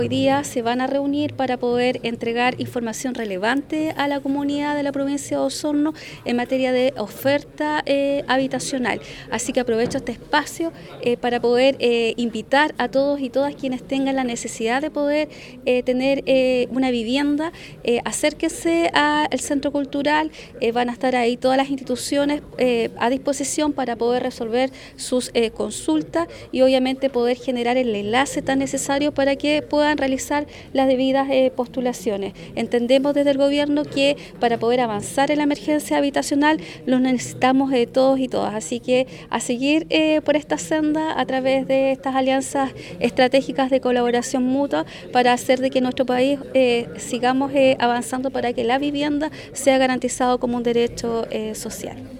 En tanto, la Delegada Presidencial Provincial Claudia Pailalef, invitó a la comunidad a ser parte de esta Feria de Vivienda donde se podrá recibir toda la información relevante para adquirir una vivienda.